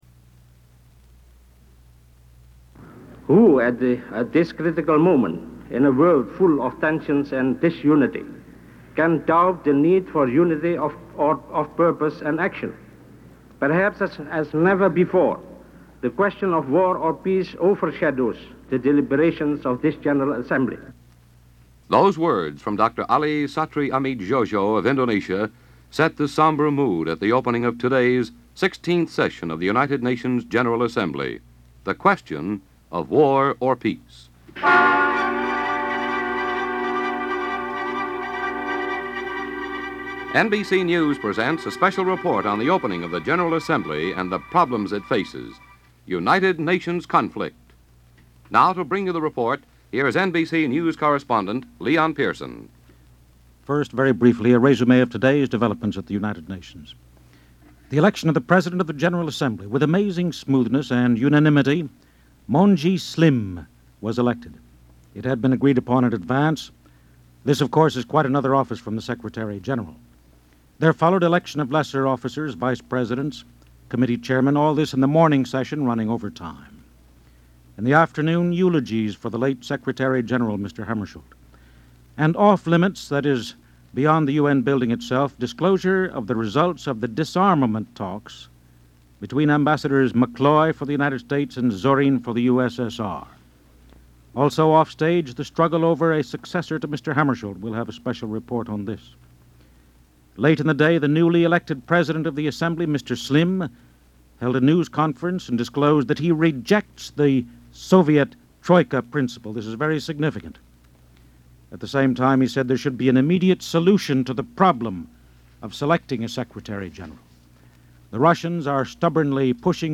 This half hour special report from NBC Radio, Conflict: War or Peace? was originally broadcast on September 20, 1961, two days after news of the death of Dag Hammarskjöld was announced and it leads the story for what is a turbulent period of time for The United Nations and the world in general.